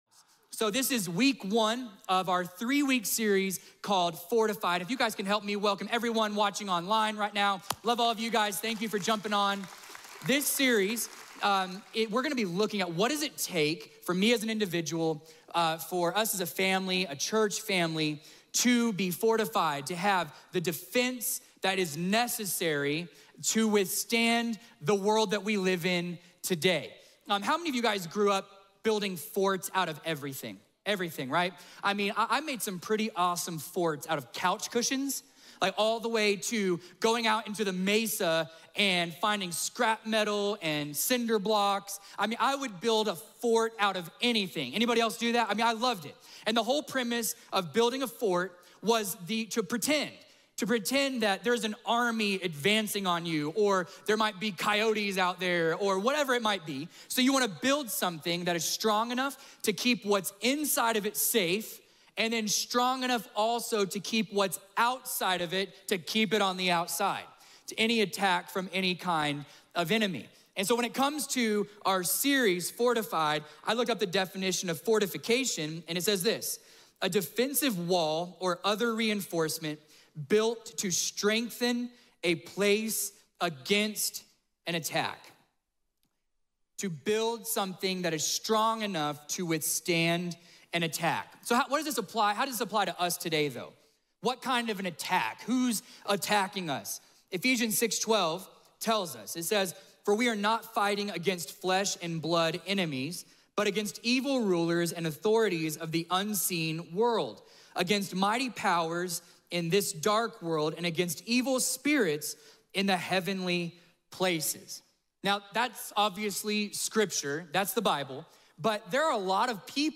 A message from the series "Fortified."